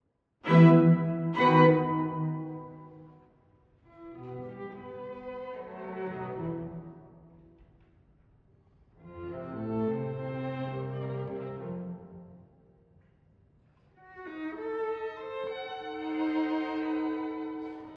↑古い録音のため聴きづらいかもしれません！（以下同様）
3曲のなかで唯一の短調。
ですが、ずっと暗くはなく、楽章ごとにさまざまな彩りを見せます。
とくに4楽章は、1楽章から打ってかわって軽快です。